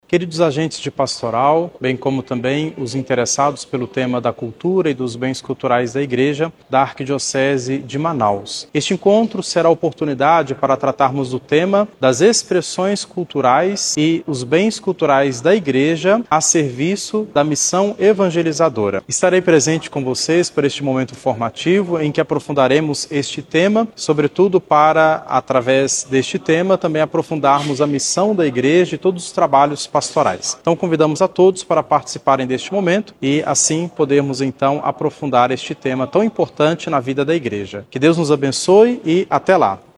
sonora-padre-.mp3